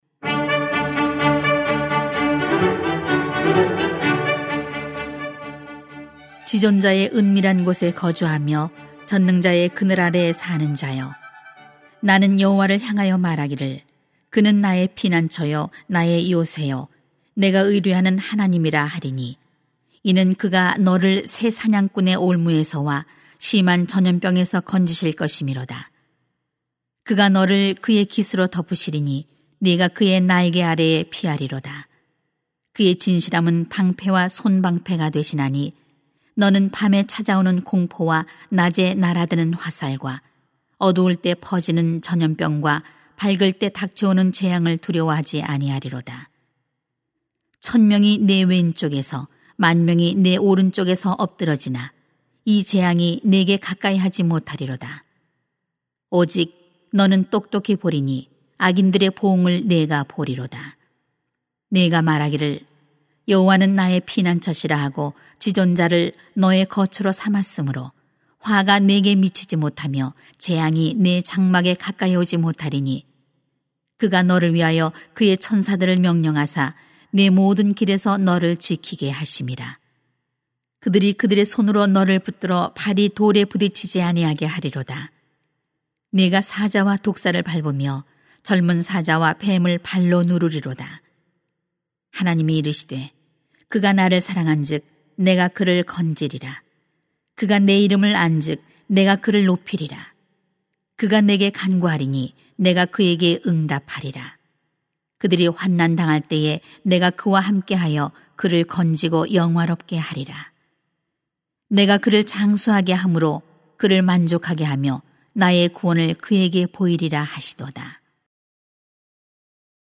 2020.2.2. 무엇을 두려워하십니까? > 주일 예배 | 전주제자교회